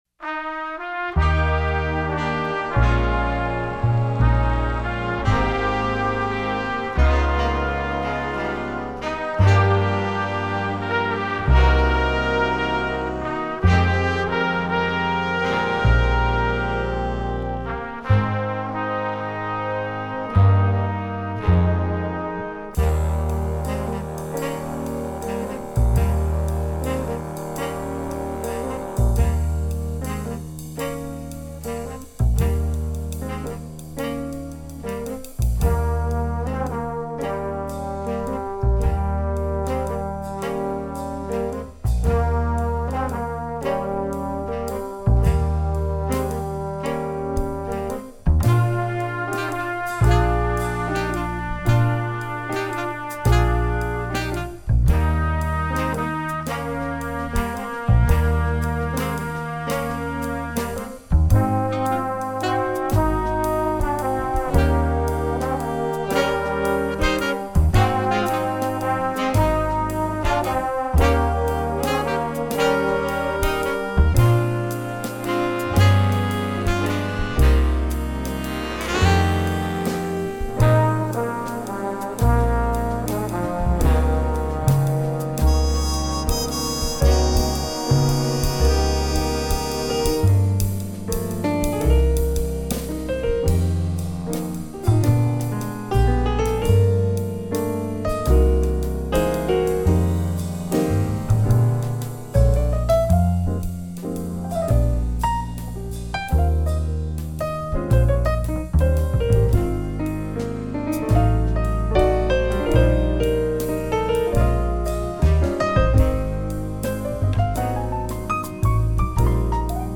Jazz - Christmas
Vocal Solo with Jazz Band